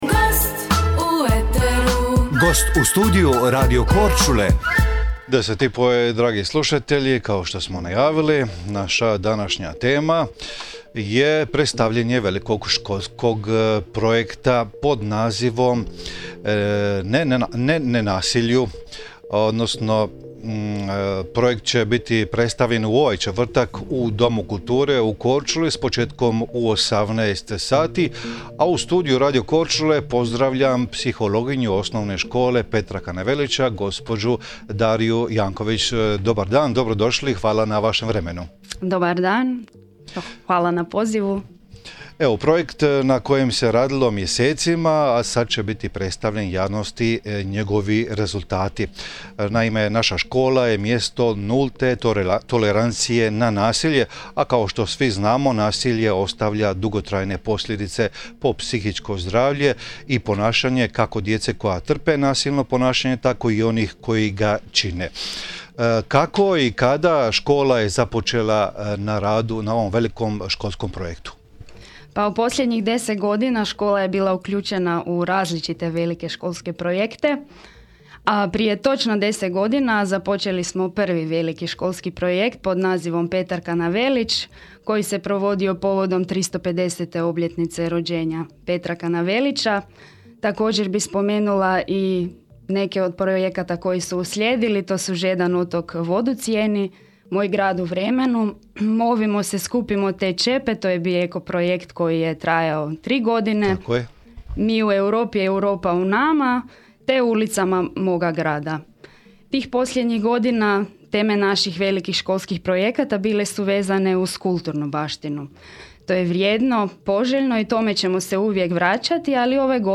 Kliknite na sliku i poslušajte radio emisiju.